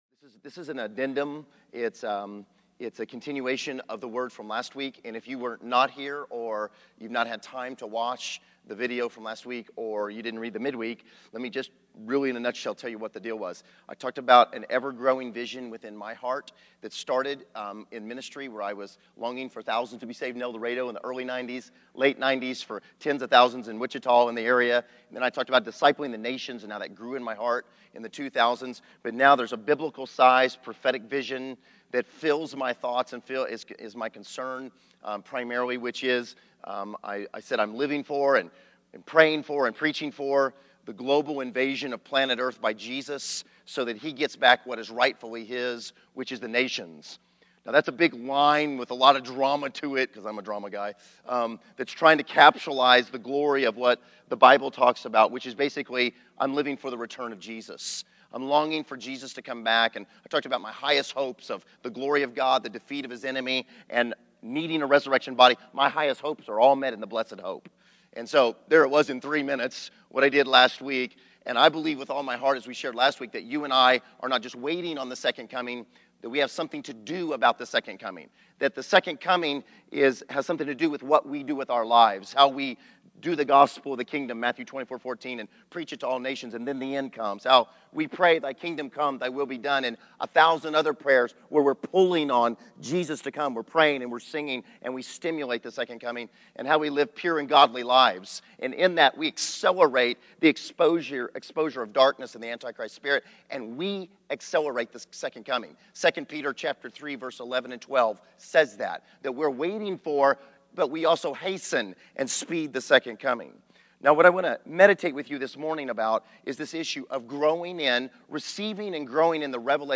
Receiving & Growing in the Revelation of Jesus as King of Kings January 04, 2015 Category: Sermons | Location: El Dorado Back to the Resource Library Video Audio To come into the kingdom you must get a revelation of Jesus as savior from sin. But to faithfully live and witness to Jesus you must receive a revelation of Jesus as King of Kings.